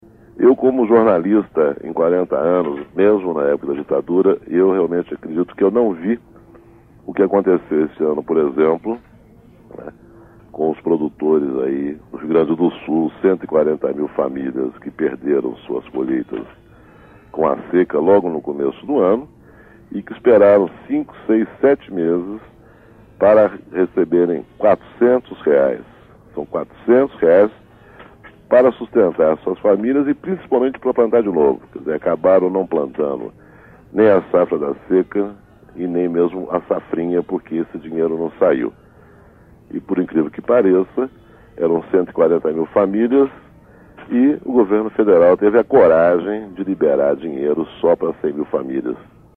Meses para receber só R$ 400 Programa A Voz da Contag Edição 194, 16/12/1996 Neste trecho da carta falada da edição 194 do programa A Voz da Contag, produzido pela Oboré em 1996, Aloysio Biondi criticou a atitude do governo em dar apoio para agricultores que perderam suas plantações por conta da seca.